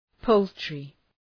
Προφορά
{‘pəʋltrı}
poultry.mp3